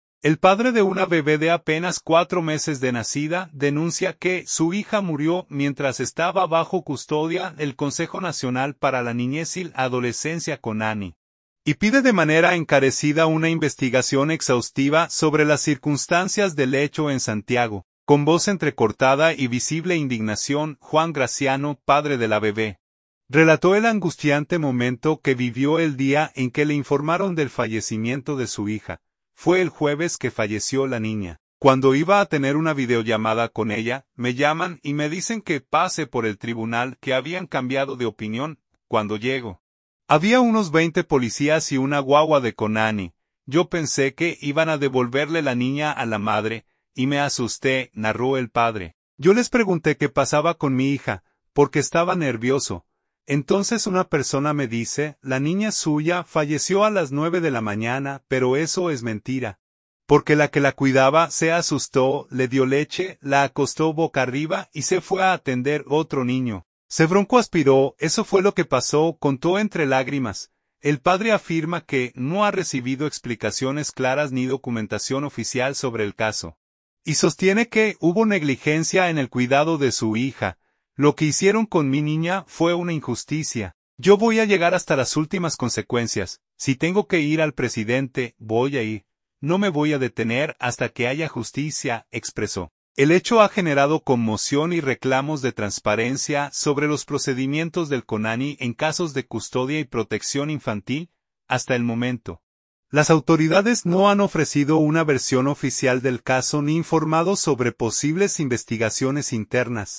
Con voz entrecortada y visible indignación